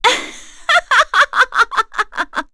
Erze-Vox_Happy3.wav